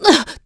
Isolet-Vox_Damage_05.wav